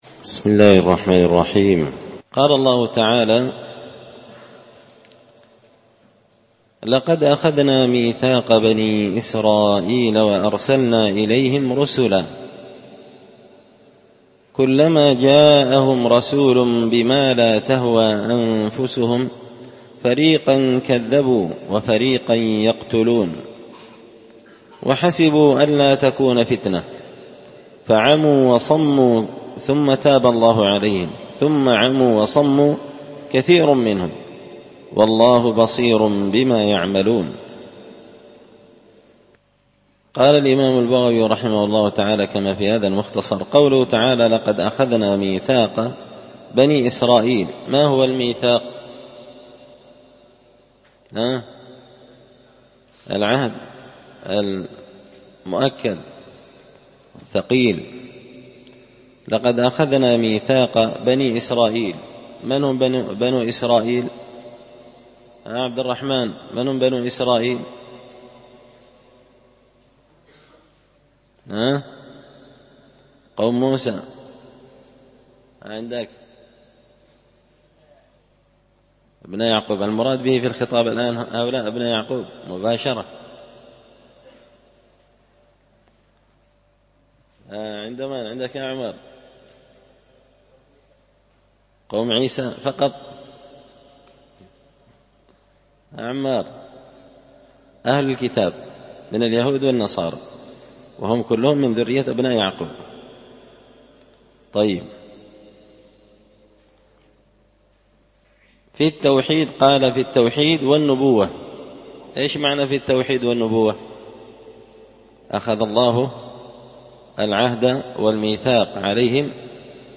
ألقيت هذه الدروس في 📓 # دار _الحديث_ السلفية _بقشن_ بالمهرة_ اليمن 🔴مسجد الفرقان